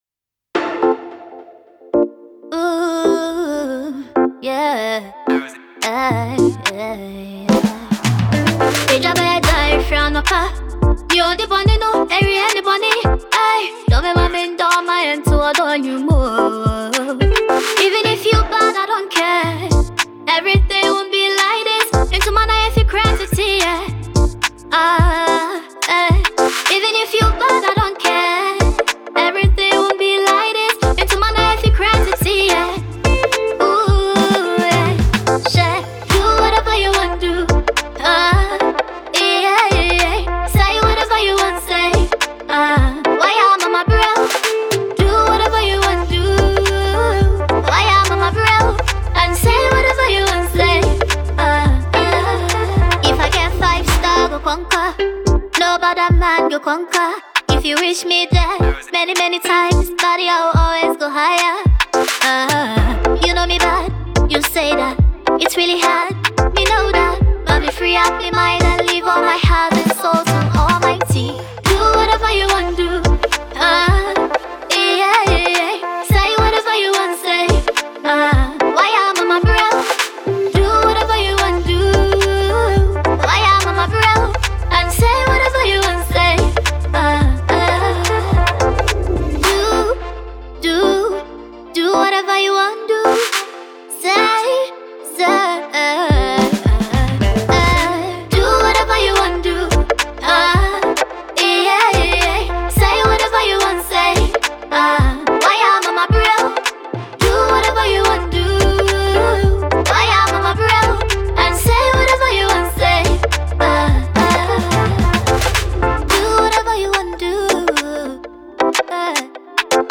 Eastern Region based most promising young female singer